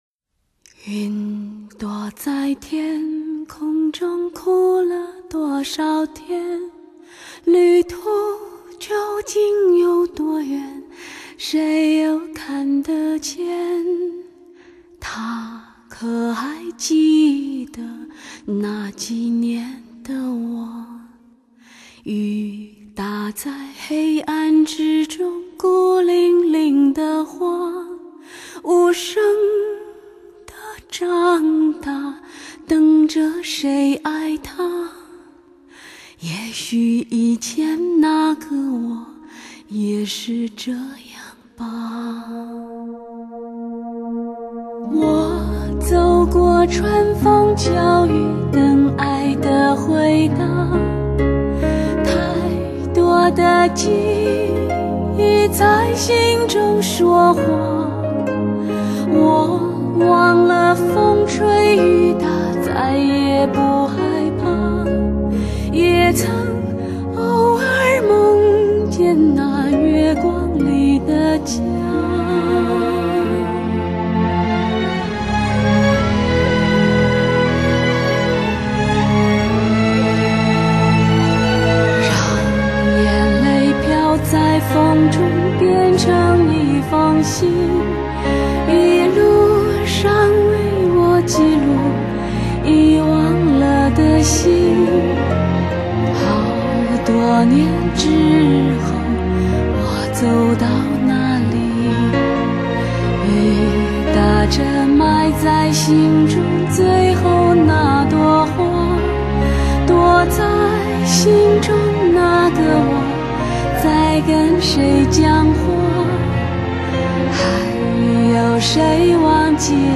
华人世界最会唱女歌手
挑战十六度音域纵横乐坛